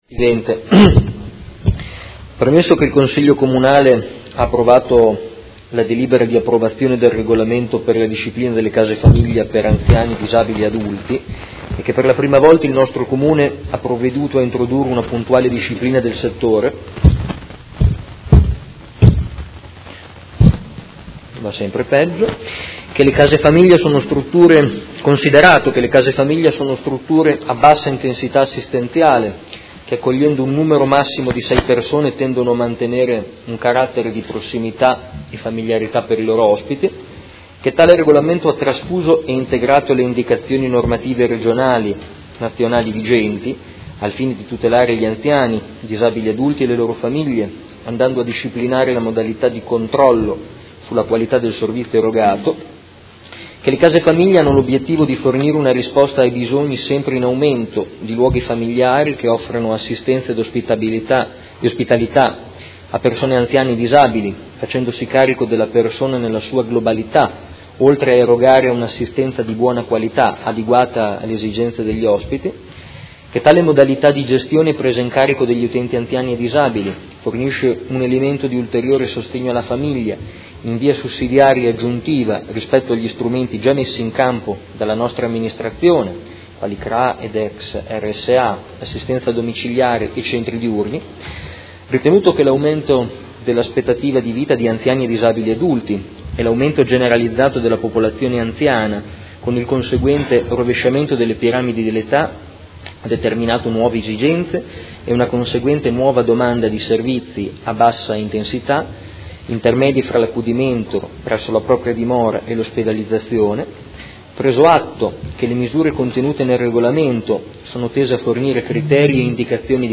Seduta del 15/03/2018. Presenta mozione su proposta di deliberazione: Approvazione del Regolamento per la Disciplina delle Case famiglia per anziani e disabili adulti